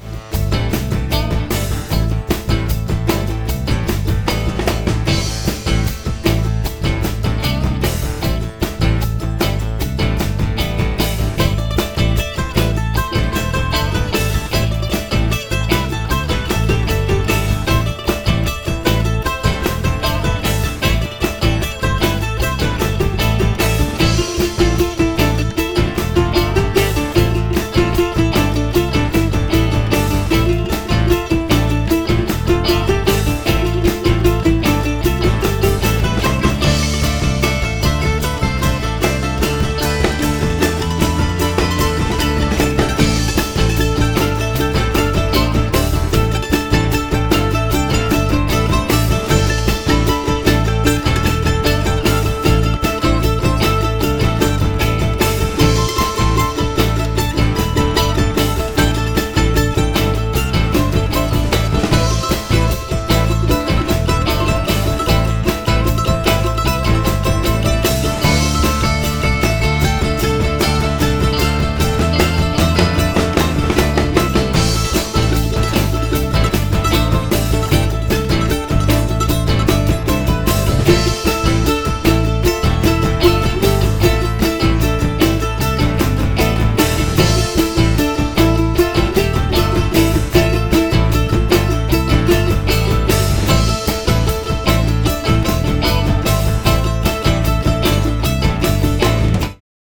All Original Indy Rock Sound